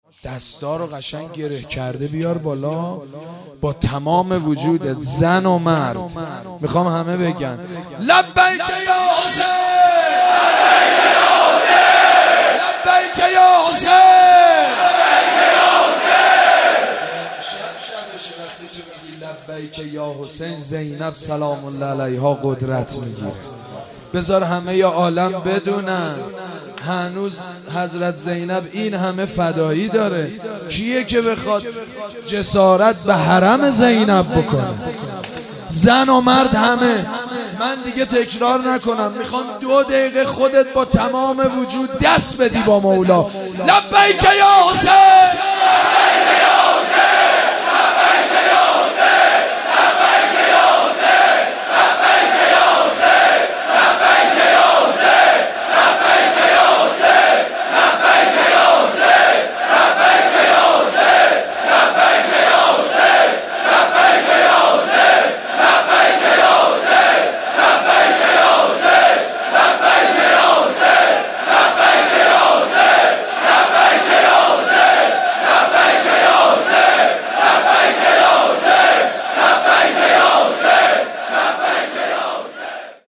روضه سیدمجید بنی‌فاطمه